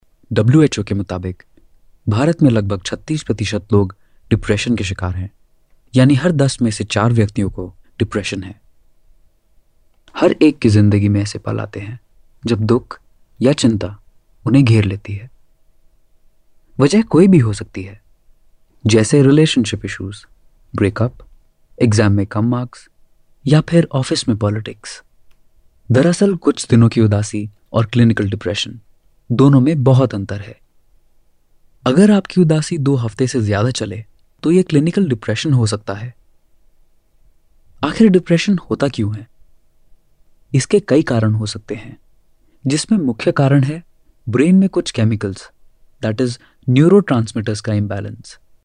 男印06 印度印地语男声 大气浑厚磁性|沉稳|娓娓道来|积极向上|时尚活力|神秘性感